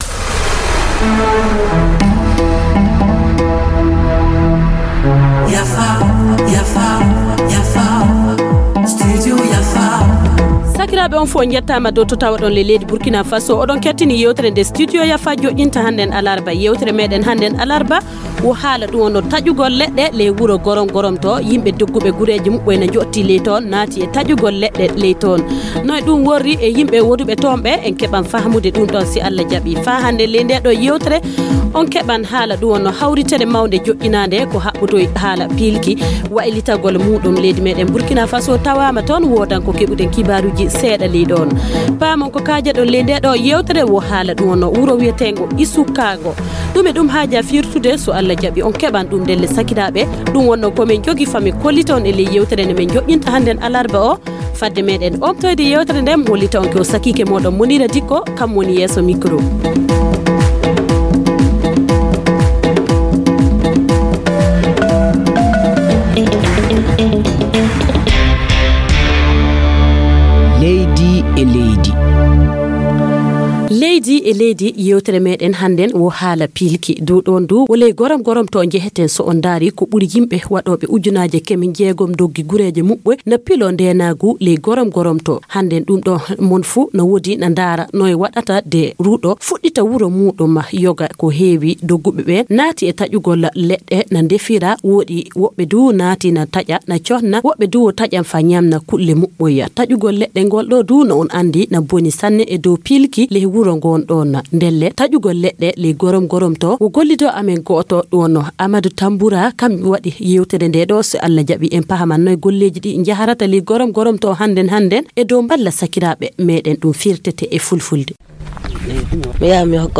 Reportage: A Gorom-Gorom, pour certains, la survie passe par la coupe abusive du bois Entretien